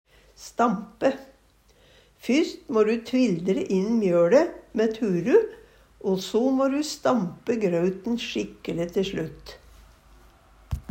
stampe - Numedalsmål (en-US)